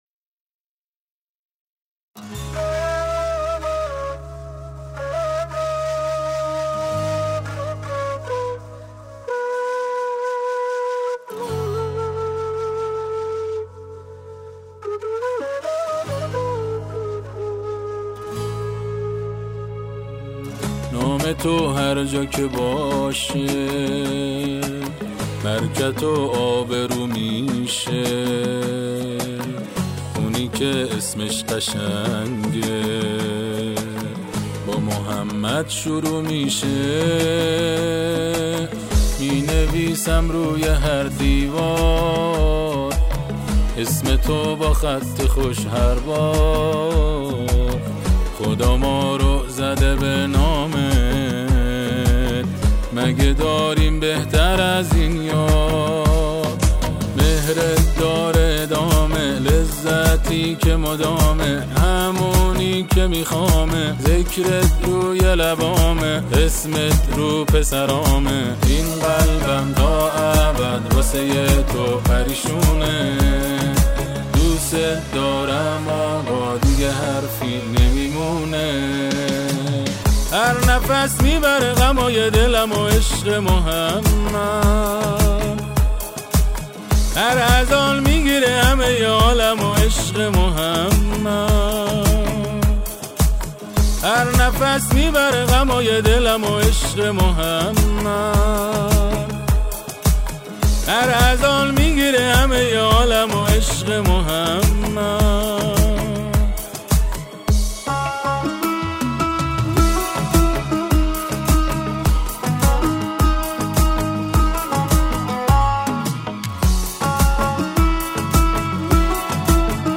همخوانی